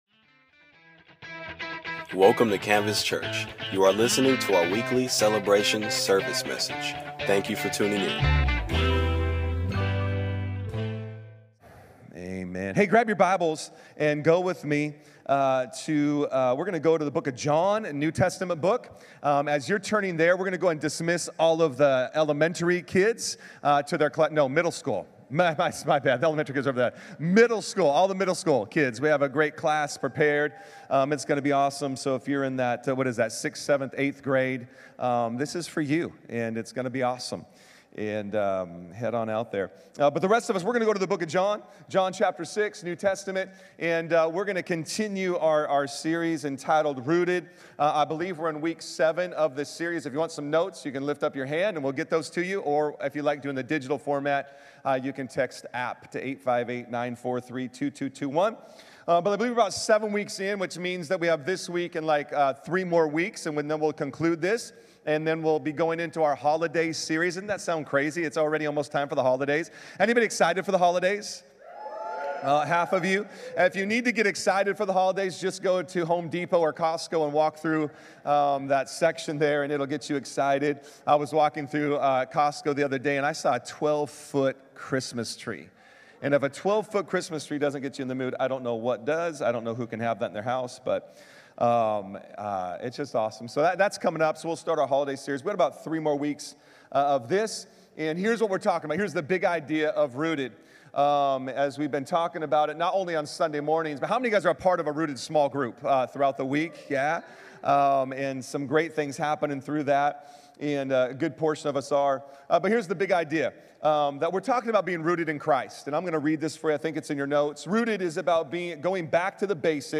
Solo tienes que ver y escuchar nuestro audio y vídeo antes de venir un domingo.